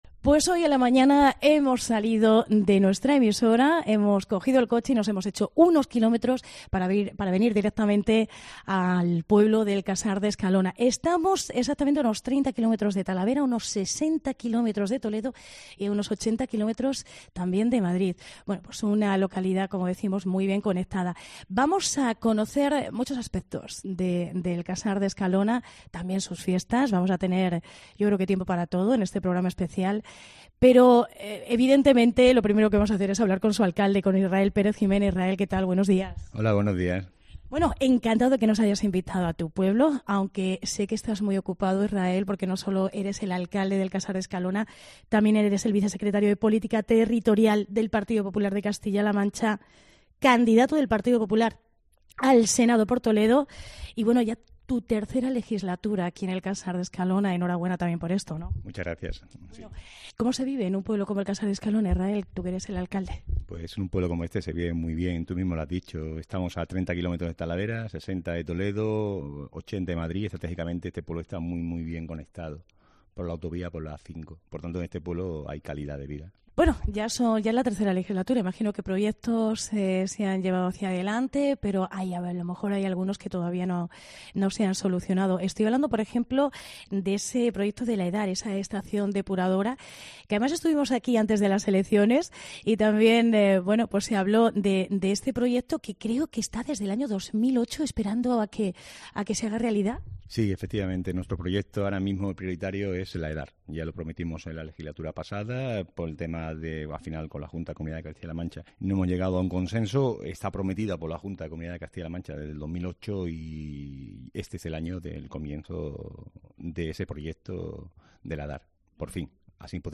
Hablamos con el alcalde, Israel Pérez, y con los concejales Concha Pastor y Gonzalo Durán de las fiestas de San Roque 2023
Programa especial desde El Casar de Escalona